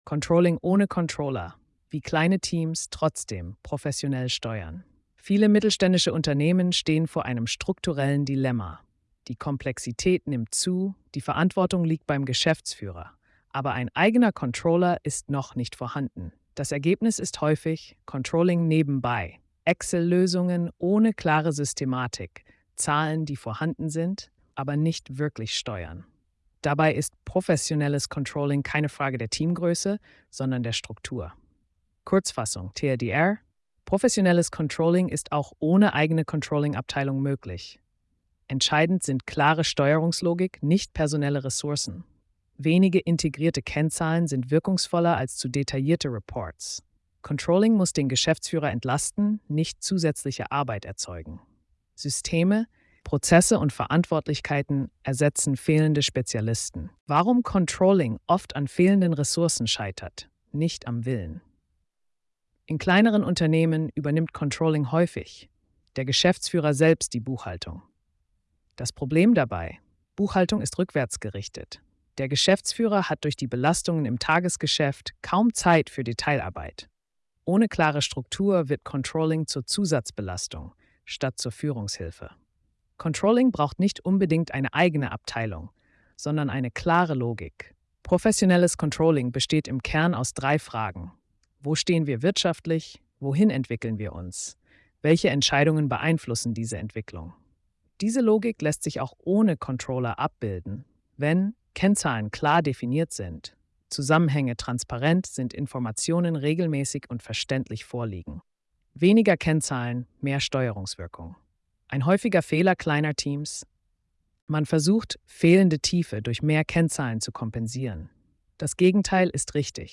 Symbolbild Sprachausgabe